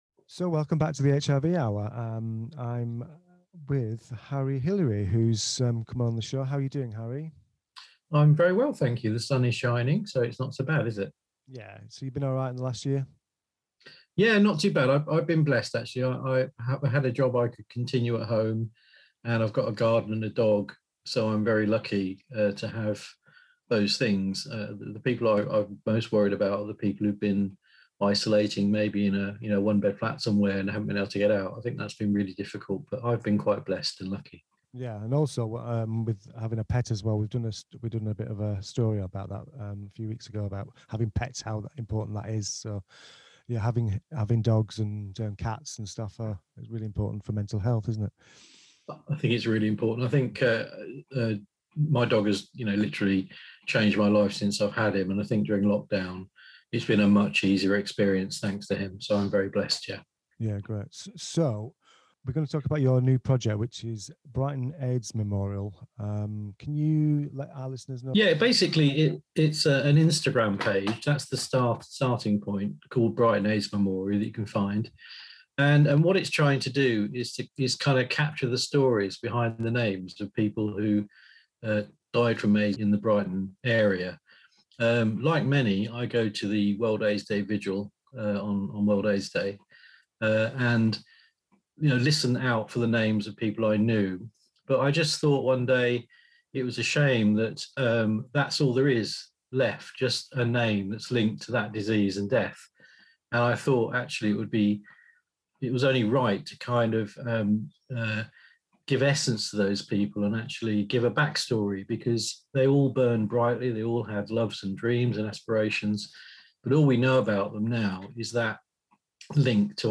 Description: HIV Hour interview which was broadcast on 13 May 2021 on Brighton local community radio, Radio Reverb.